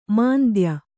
♪ māndya